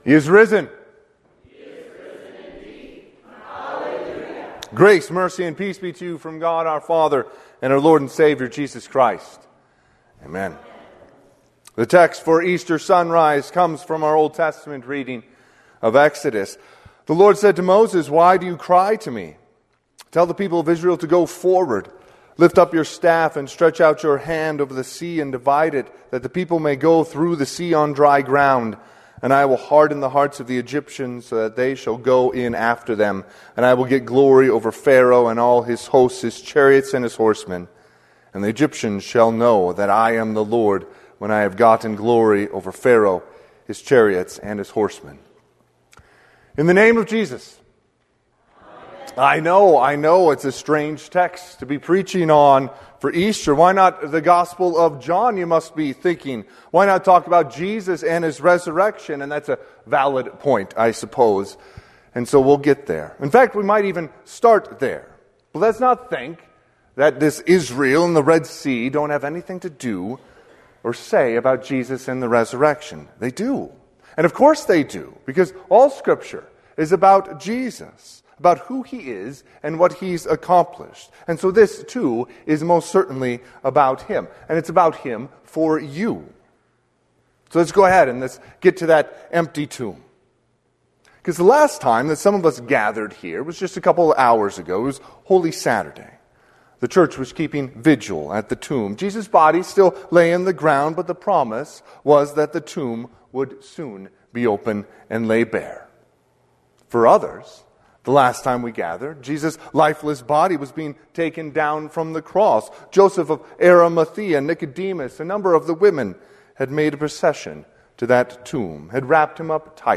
Sermon – 4/5/2026 (Sunrise) - Wheat Ridge Evangelical Lutheran Church, Wheat Ridge, Colorado